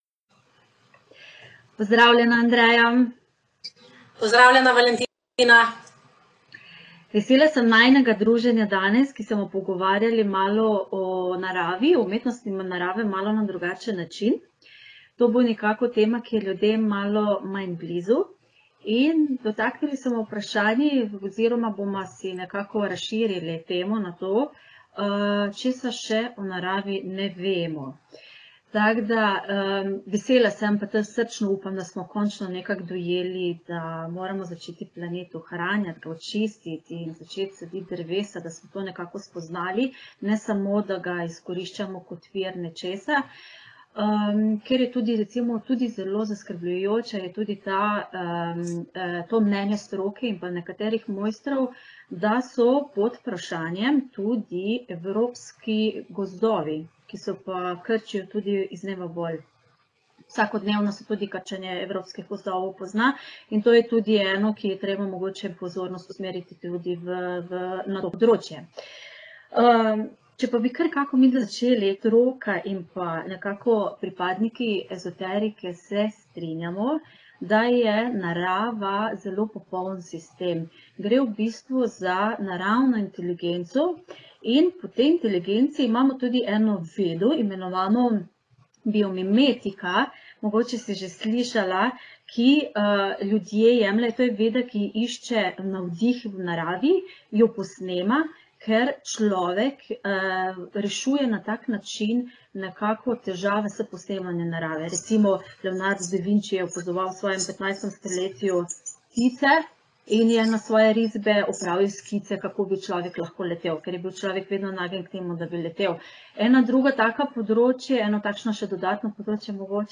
Najina telekonferenca se tako osredinja na pomembne informacije o naravi, kako nam ta lahko pomaga z vidika ezoterike in pozitivne psihologije.
pogovor-o-naravi.mp3